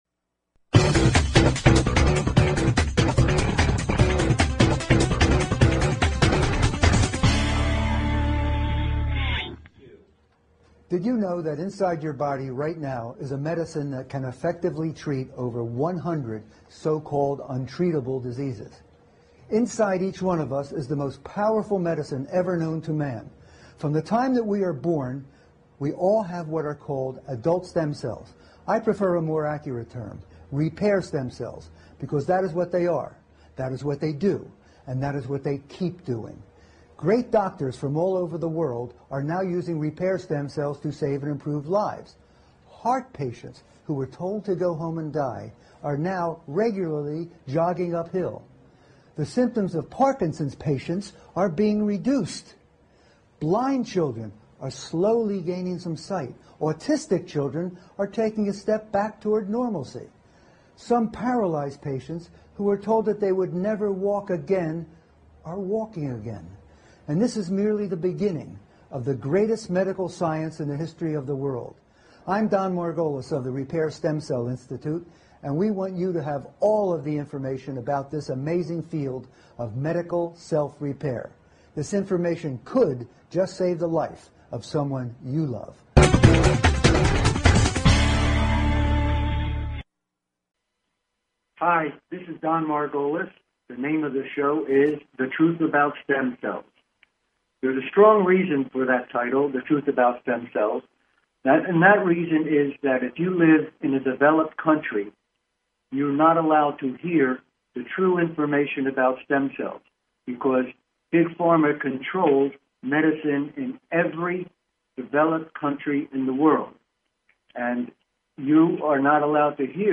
Talk Show Episode
He will tell you what to look out for, and how to get one of the few competent stem cell doctors to look at your case. He will have guest doctors every other week and, of course, he will take your calls.